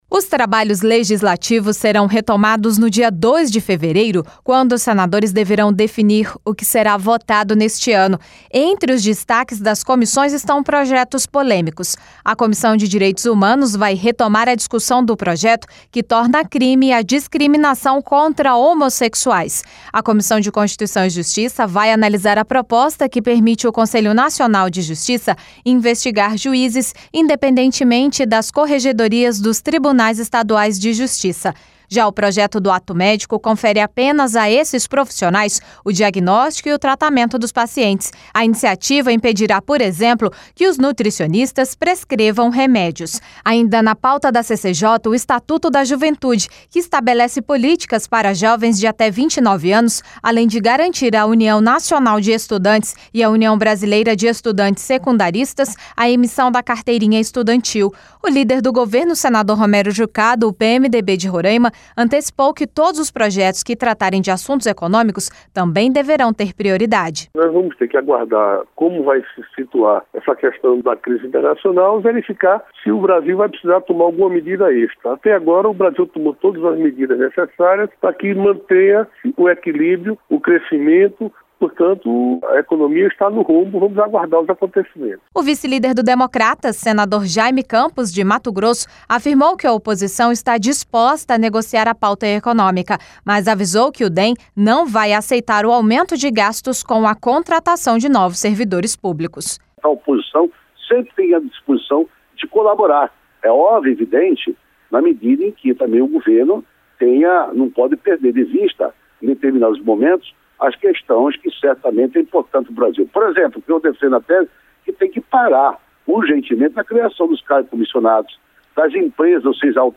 LOC: O LÍDER DO GOVERNO DESTACOU QUE TEMAS ECONÔMICOS DEVERÃO TER PRIORIDADE EM FUNÇÃO DA CRISE FINANCEIRA. A REPORTAGEM